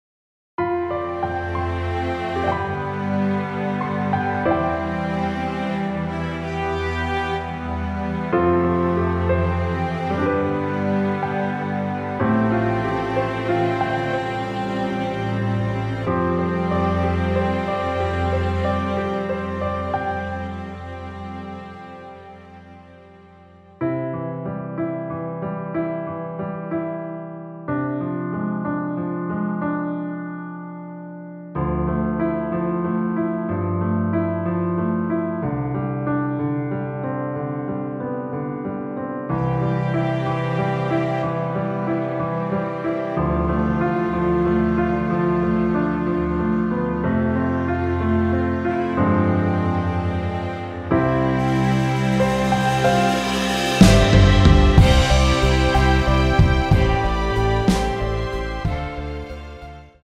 원곡보다 짧은 MR입니다.(아래 재생시간 확인)
원키에서(-1)내린 (짧은편곡)MR입니다.
앞부분30초, 뒷부분30초씩 편집해서 올려 드리고 있습니다.
중간에 음이 끈어지고 다시 나오는 이유는